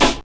JACK CRACK.wav